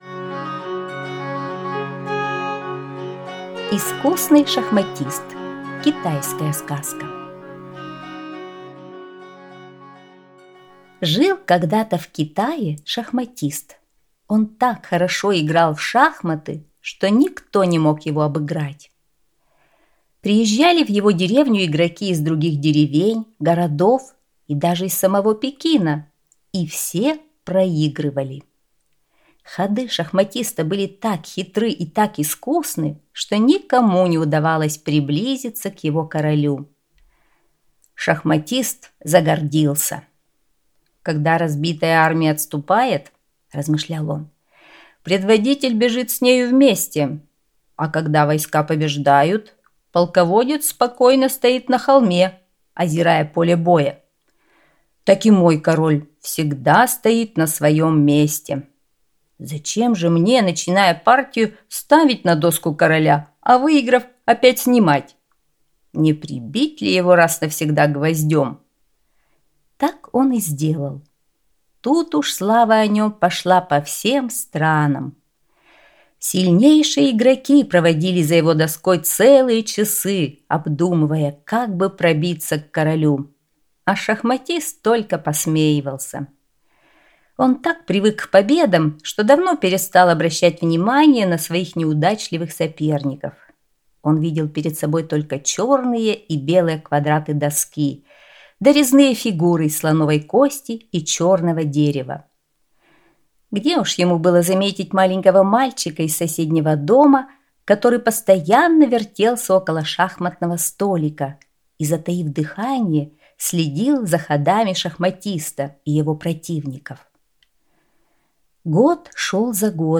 Искусный шахматист – китайская аудиосказка